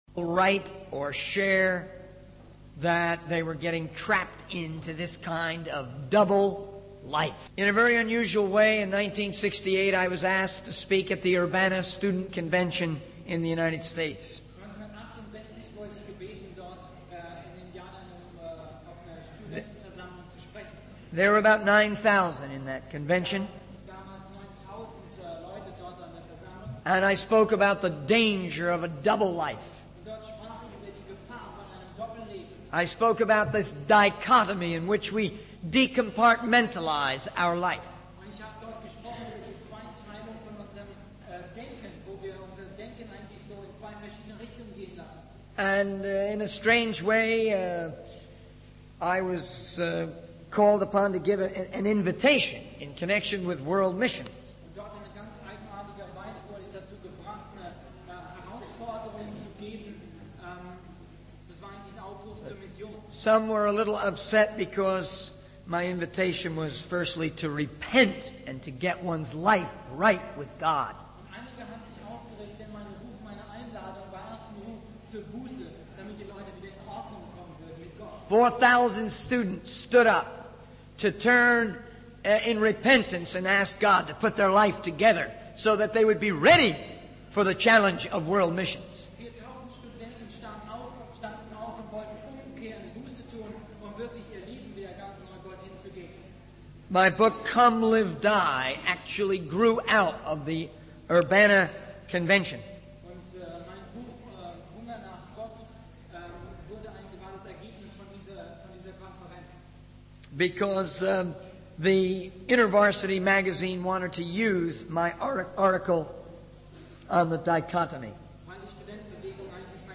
In this sermon, the speaker emphasizes the importance of hating sin and embracing goodness according to the teachings of the Bible.